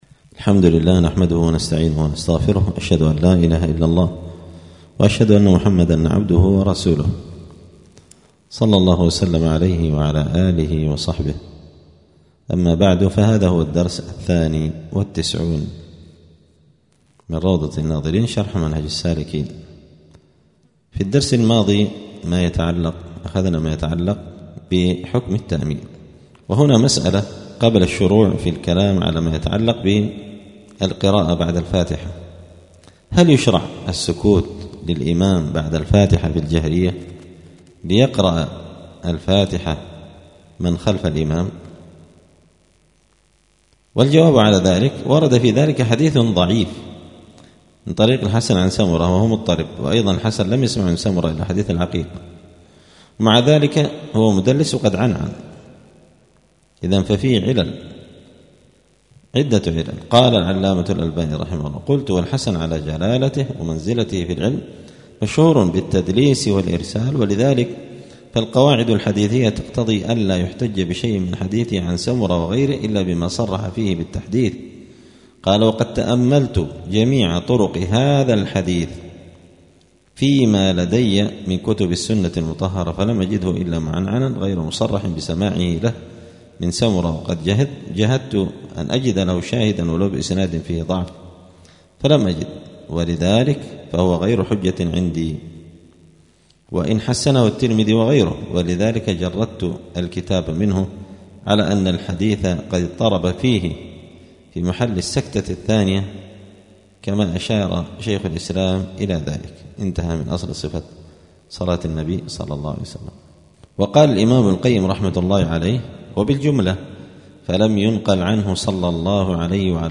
*الدرس الثاني والتسعون (92) {كتاب الصلاة باب صفة الصلاة حكم القراءة بعد الفاتحة}*